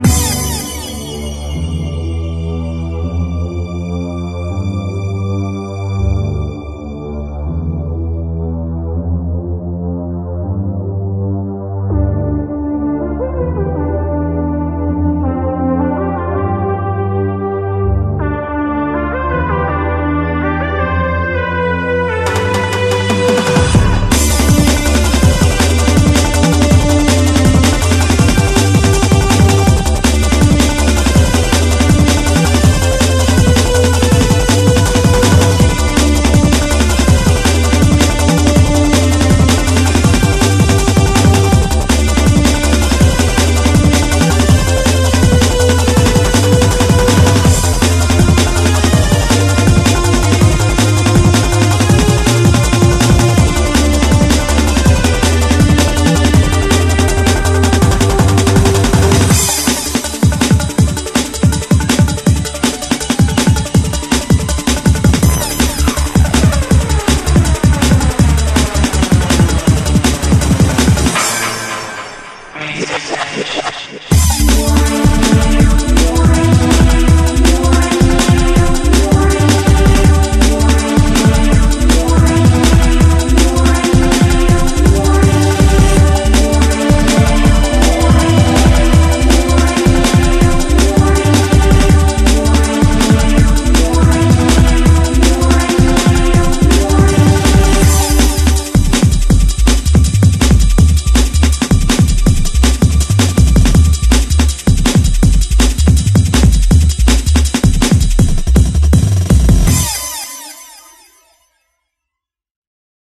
BPM162
It kinda "introduced" me to drum 'n' bass.